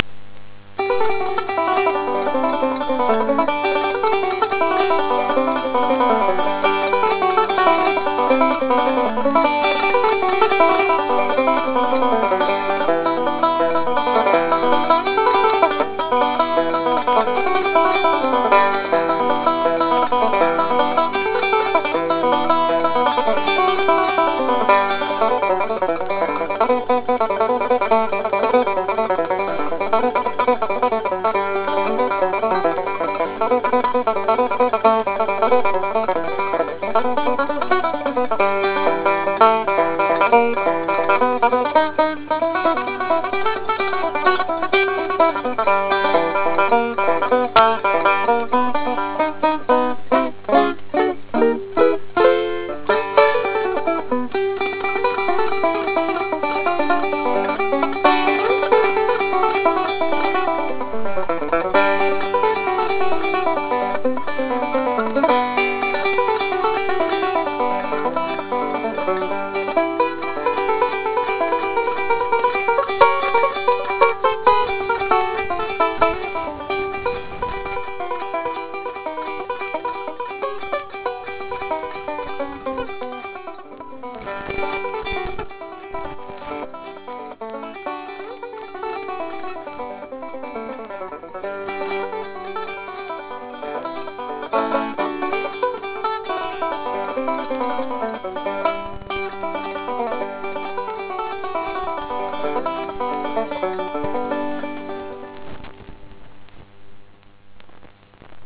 Banjo Out-takes
Blackberry Blossom is a traditional fiddle tune. I give it some spice. Unfortunately, I also give it some goofs.